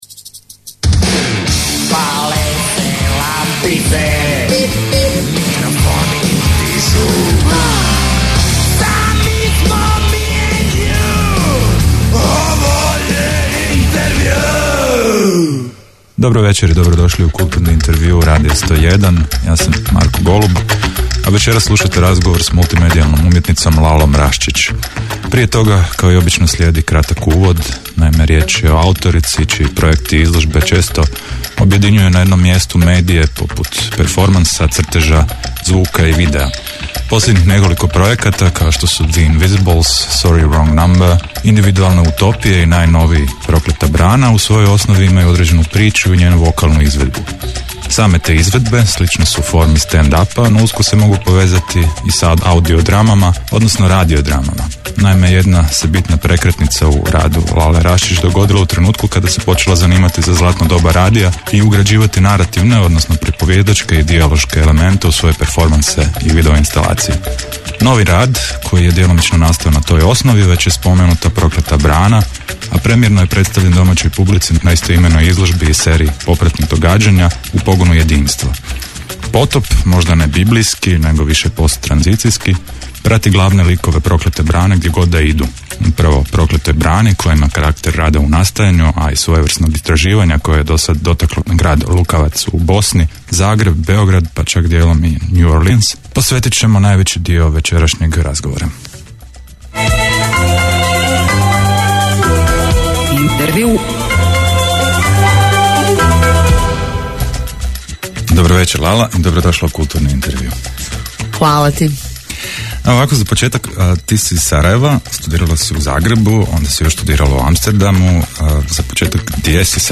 Kulturni intervju Radio 101